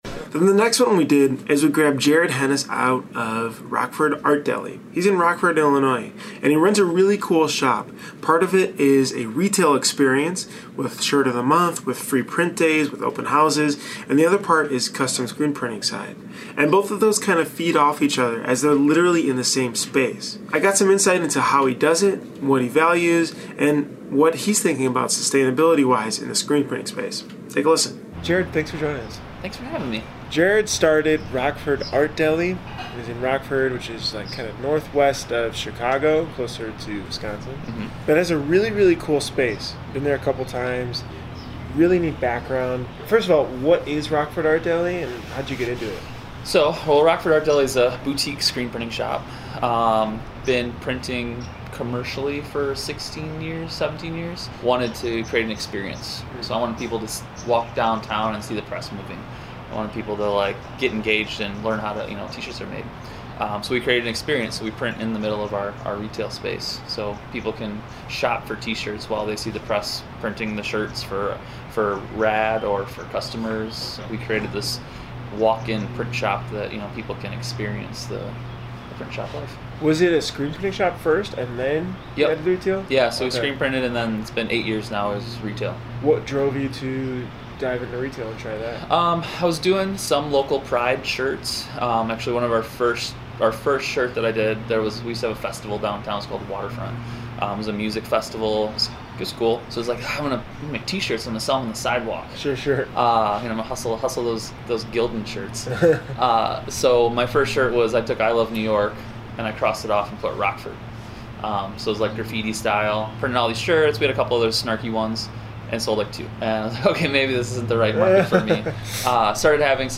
exclusive interview at THREADX 2020